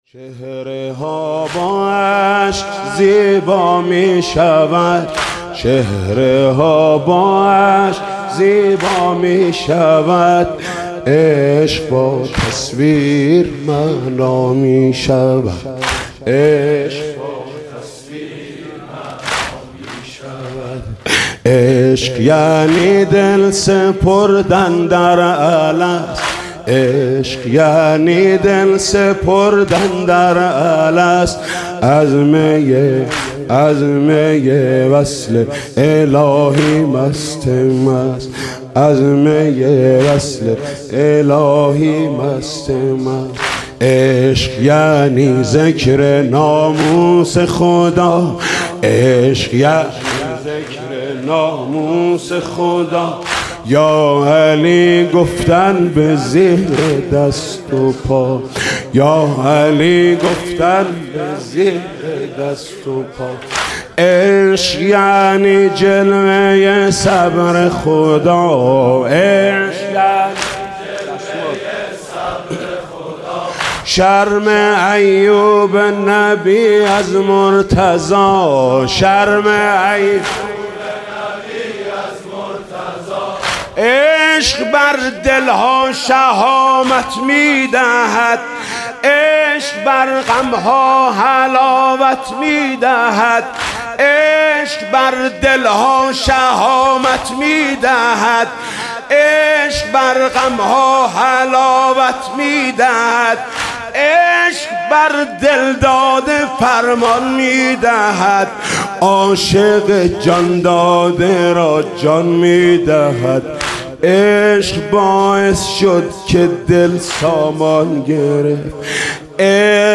چهره ها با اشک زیبا می شود واحد زیبا از محمود کریمی درباره حضرت زهرا سلام الله علیها در هیئت رایة العباس در سال ۱۳۹۷